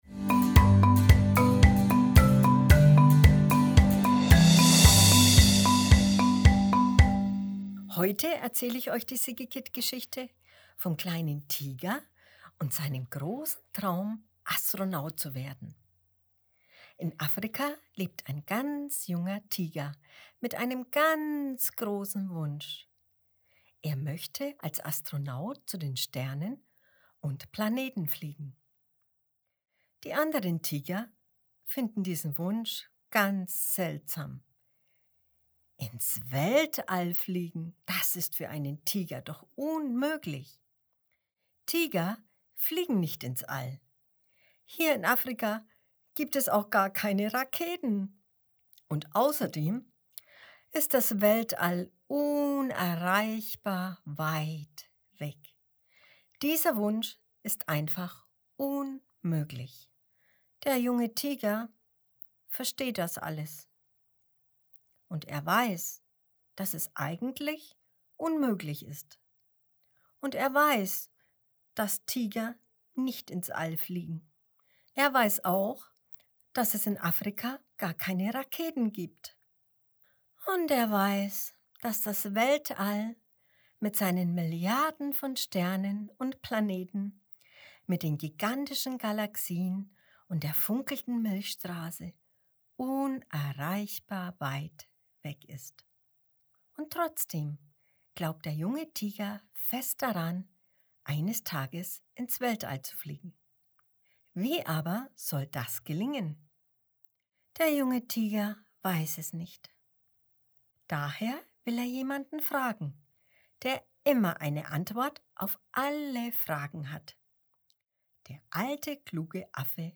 Januar 2022 Kinderblog Vorlesegeschichten In Afrika lebt ein kleiner Tiger mit einem großen Wunsch: Er möchte als Astronaut zu den Sternen fliegen.